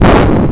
sfx_expl_orb.wav